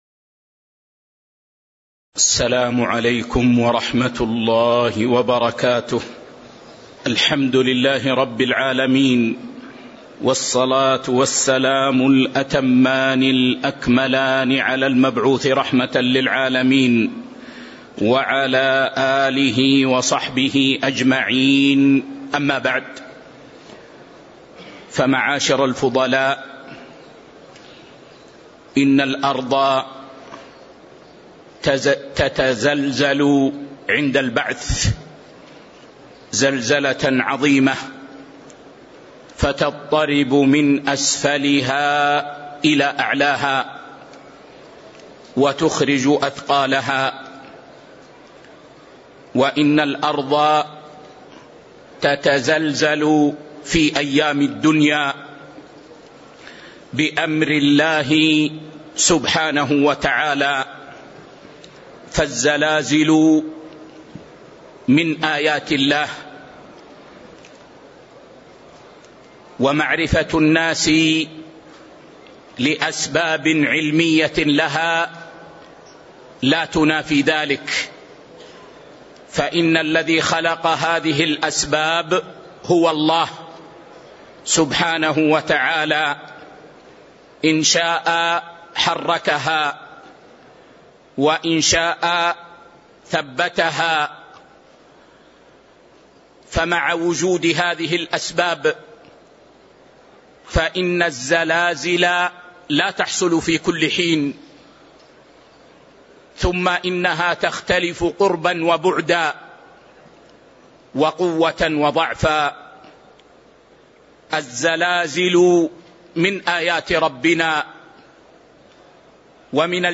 تاريخ النشر ٢٤ صفر ١٤٤٥ هـ المكان: المسجد النبوي الشيخ